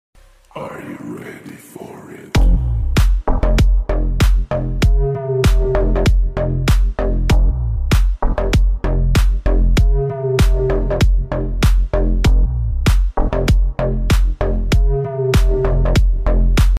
The Best Wood Splitter Ever sound effects free download